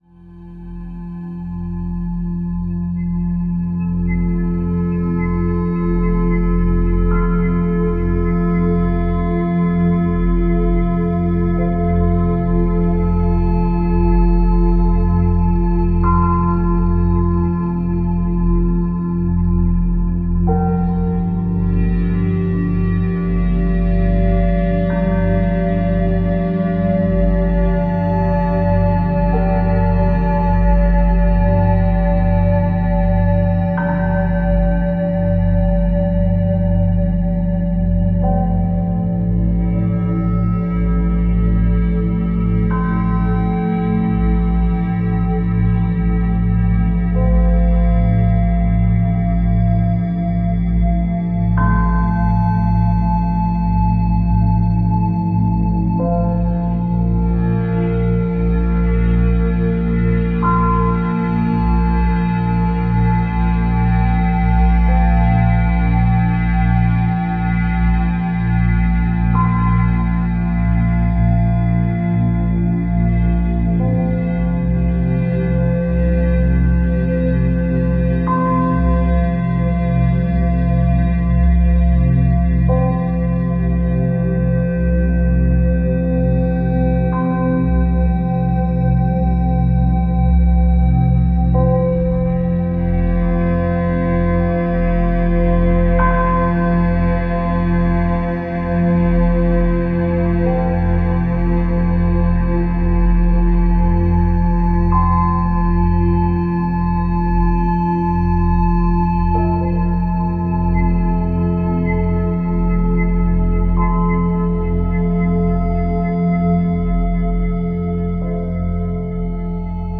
• Profi-Mastering für Kopfhörer und gute Lautsprecher
174 Hz Meditationsmusik | 30 Minuten | Grundstimmung 432 Hz